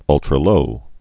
(ŭltrə-lō)